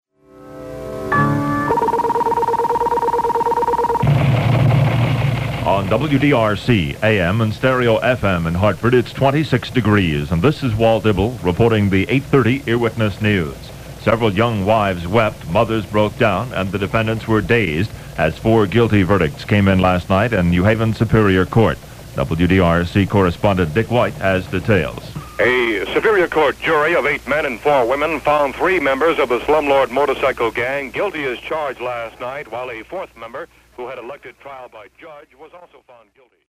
The music bed was later used as a bed for a Connecticut School of Broadcasting commercial.